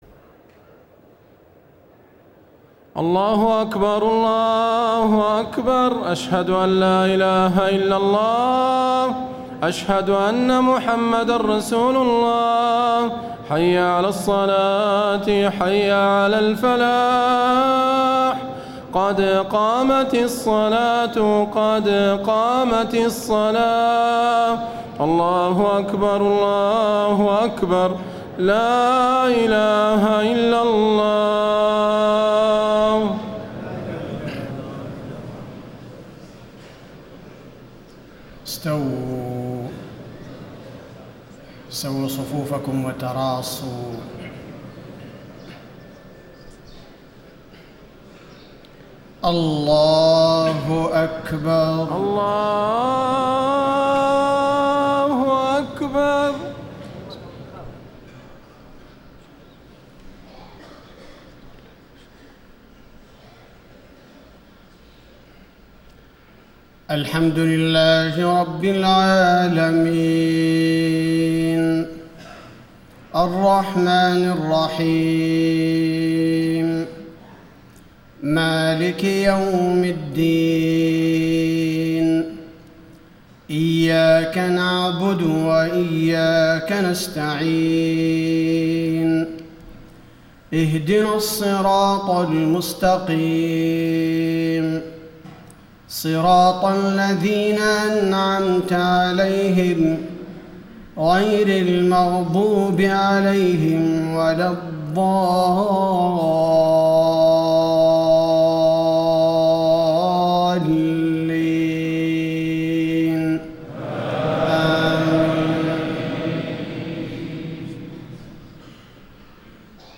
صلاة المغرب 1-4-1435هـ من سورة النور > 1435 🕌 > الفروض - تلاوات الحرمين